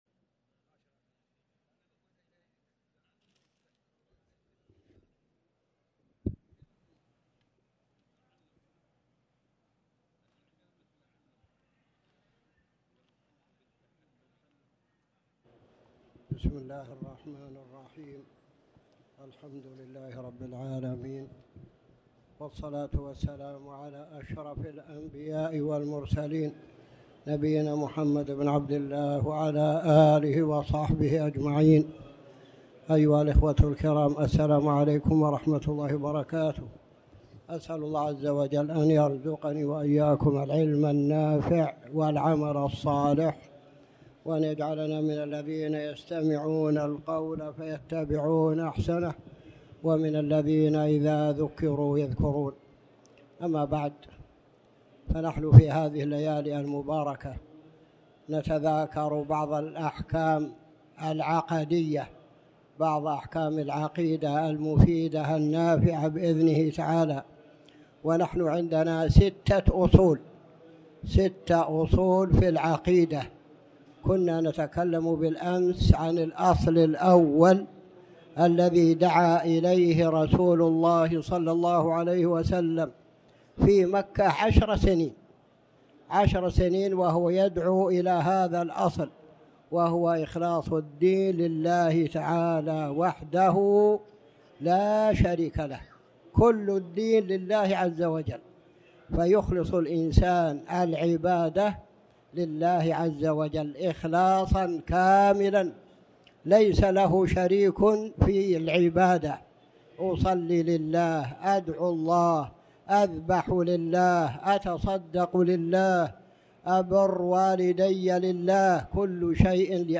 تاريخ النشر ١٢ جمادى الآخرة ١٤٣٩ هـ المكان: المسجد الحرام الشيخ